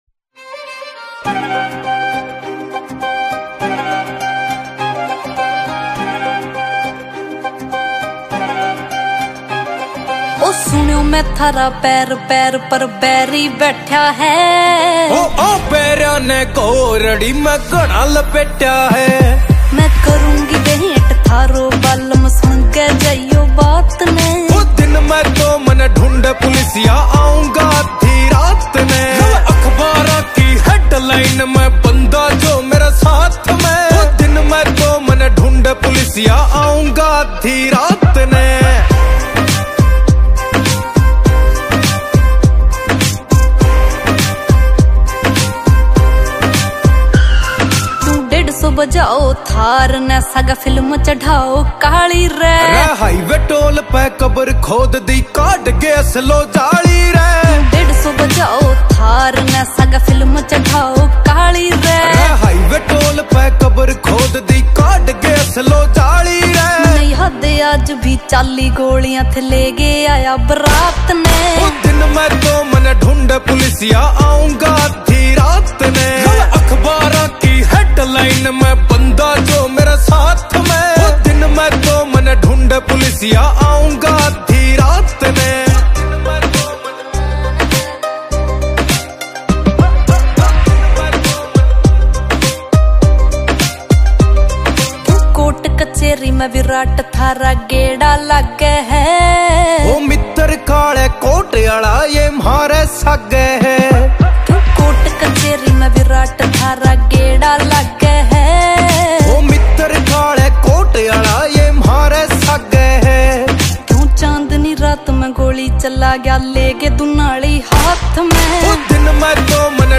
Releted Files Of Latest Haryanvi song